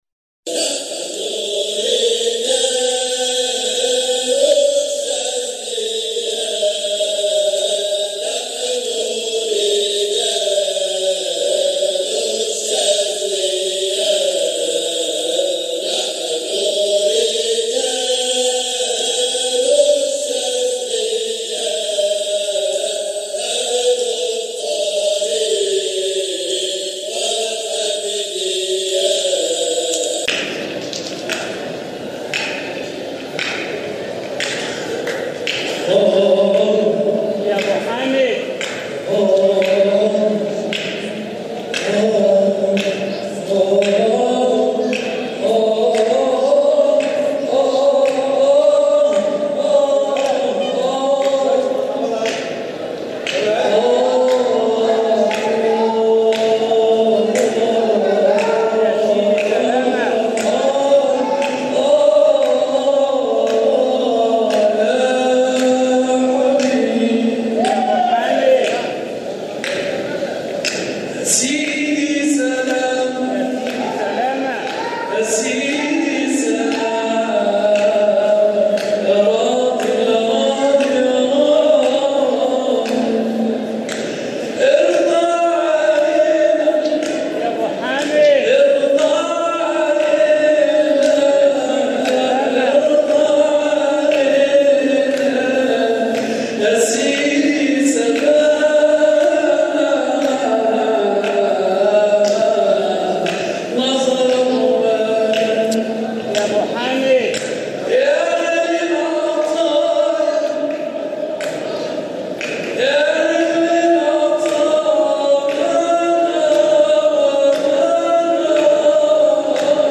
مقاطع من احتفالات ابناء الطريقة الحامدية الشاذلية بمناسباتهم
حلقة ذكر بمسجد سيدنا ابراهيم الدسوقي